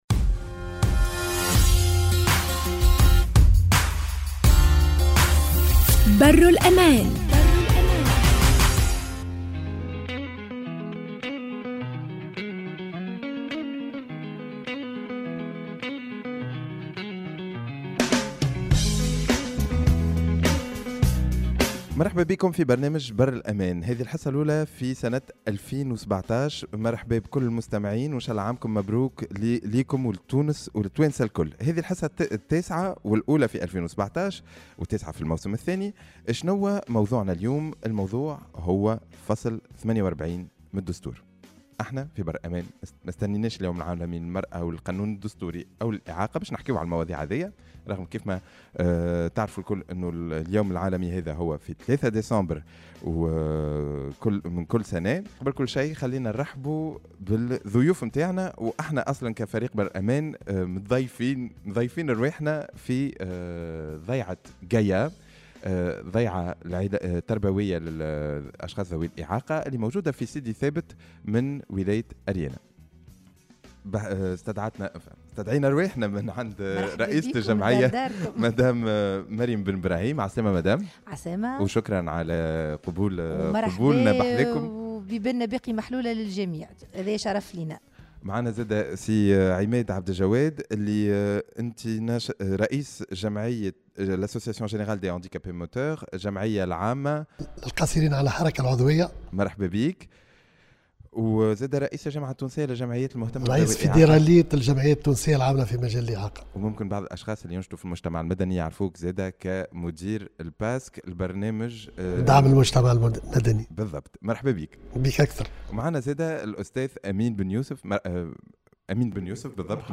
L’émission a été enregistrée dans le siège de l’Association GAIA à Sidi Thabet. Et l’objectif était l’évaluation d’un article de la Constitution de 2014 et plus précisément l’article 48.